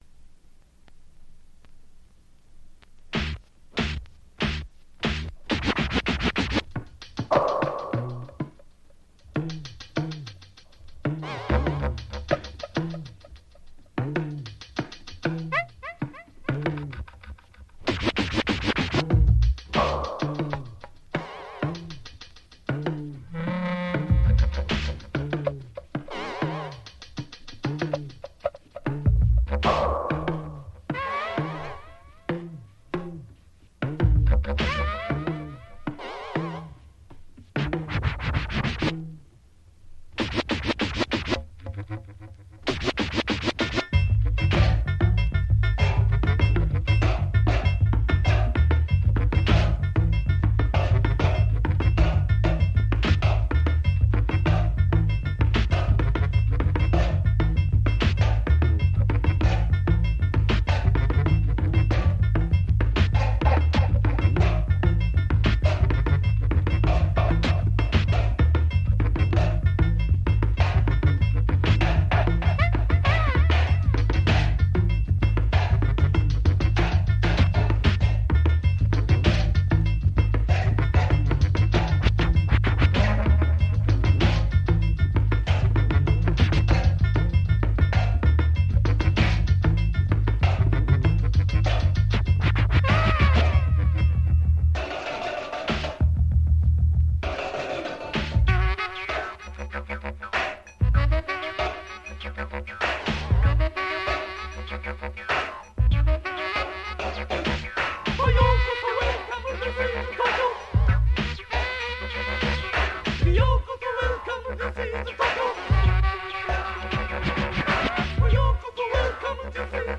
(instrumental)
Basic track recorded at Evergreen Studio, New York City
Overdubbed at Smoky Studio, Tokyo
Mixed at CBS Sony Roppongi Studio, Tokyo
talking drum